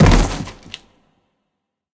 anonTheaterFall.ogg